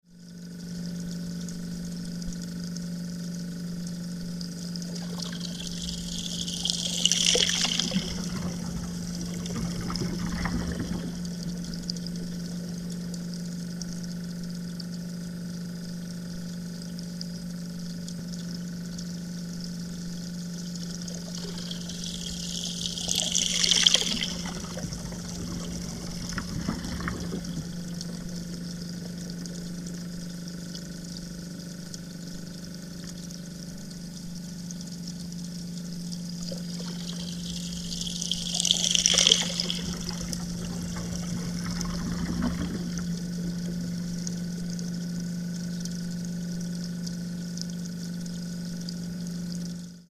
acoustic views of the interior of works of Art
A minimum of sound processing is used on these recordings
Fountains
an acoustic perspective of some of